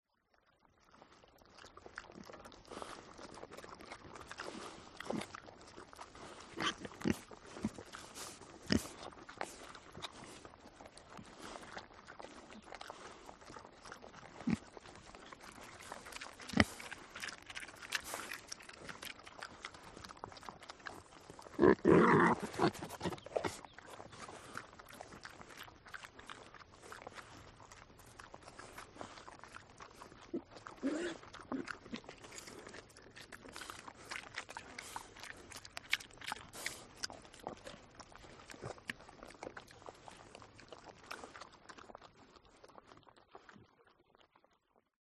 Звуки свиньи
Звук поедающей что-то свиньи